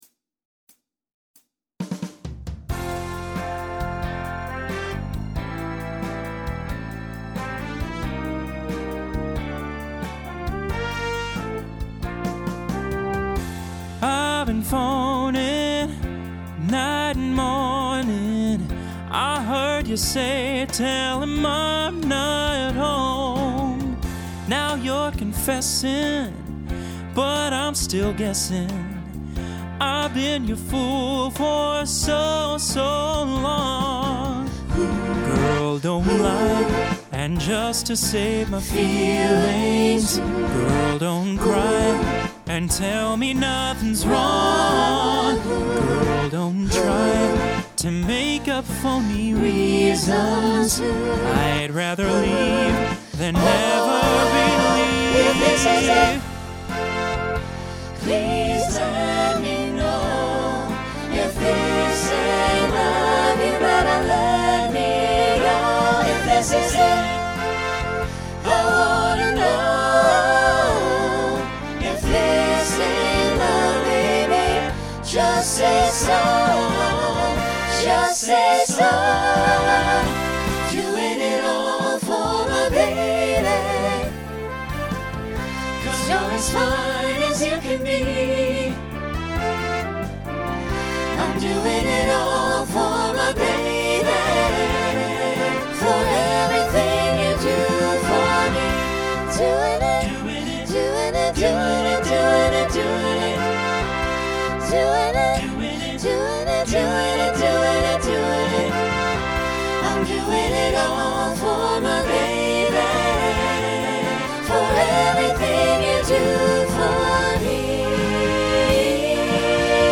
All SATB except Workin' For A Livin', which is SSA.
Voicing Mixed